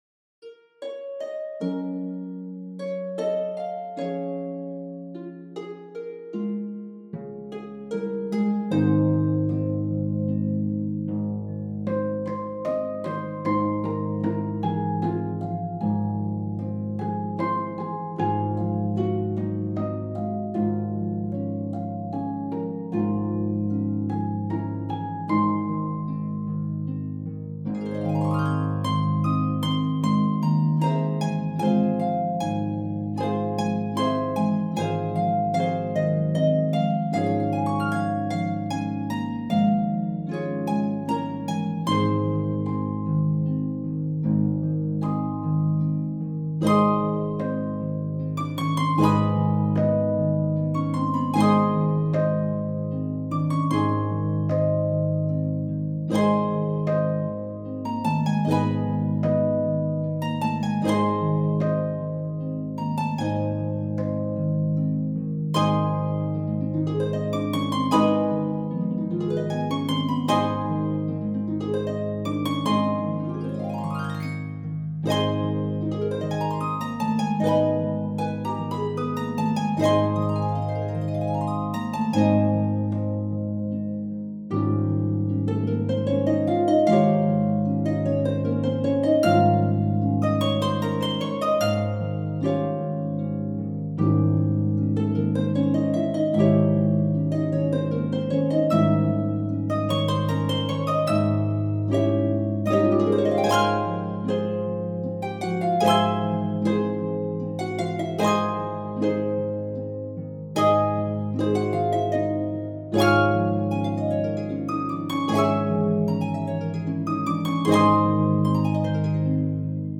is for four pedal harps